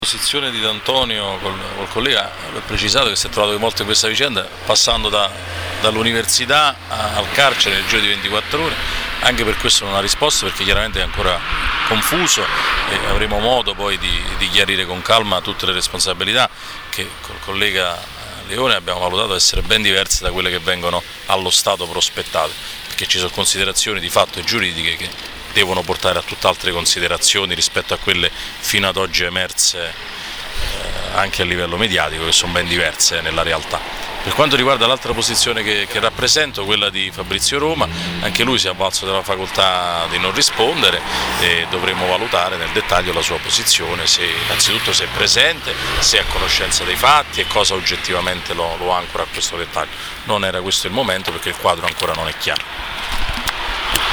Per i dettagli sulle dichiarazioni ascoltare le interviste agli avvocati difensori rilasciate subito dopo l’interrogatorio:
L’intervista